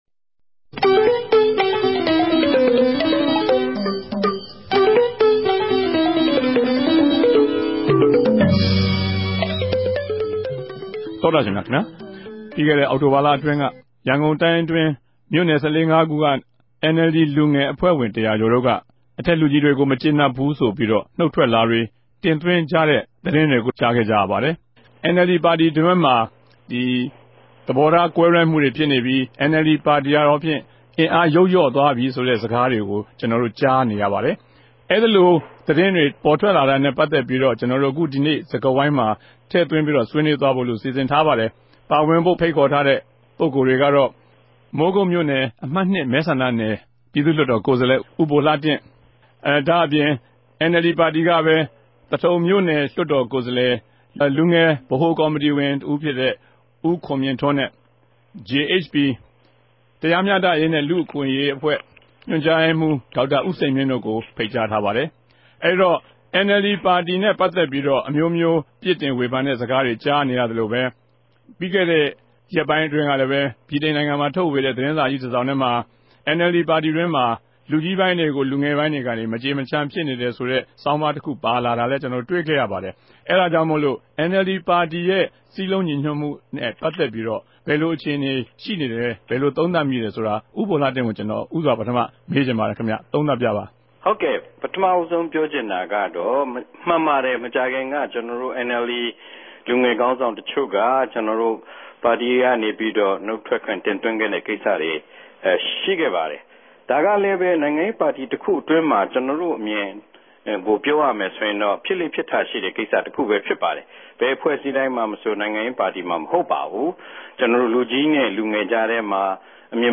တနဂဿေိံြ စကားဝိုင်း။